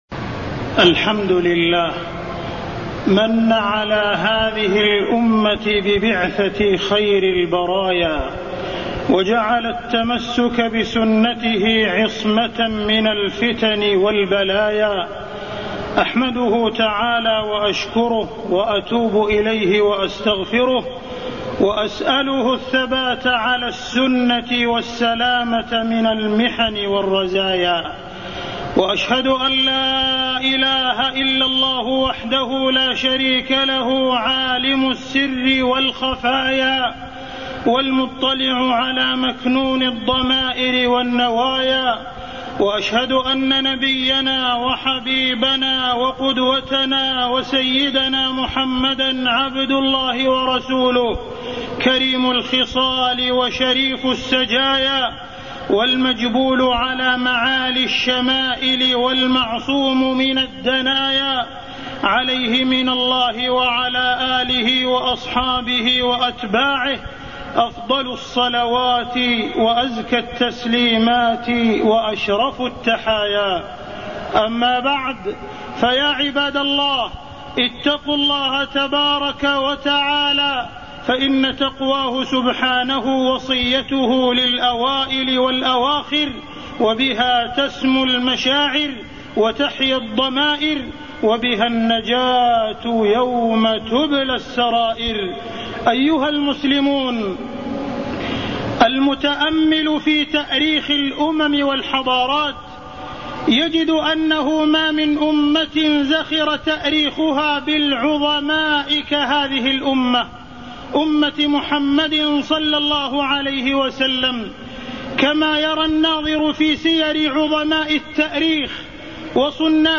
تاريخ النشر ٧ ربيع الأول ١٤٢١ هـ المكان: المسجد الحرام الشيخ: معالي الشيخ أ.د. عبدالرحمن بن عبدالعزيز السديس معالي الشيخ أ.د. عبدالرحمن بن عبدالعزيز السديس سيرة النبي صلى الله عليه وسلم